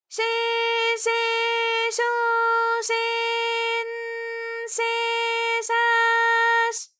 ALYS-DB-001-JPN - First Japanese UTAU vocal library of ALYS.
she_she_sho_she_n_she_sha_sh.wav